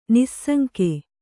♪ nissaŋke